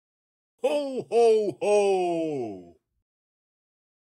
g 54cfc62e35 Switch notification sound to hohoho.mp3 and add download script
hohoho.mp3